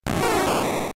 Cri de Bulbizarre ayant un statut dans Pokémon Diamant et Perle.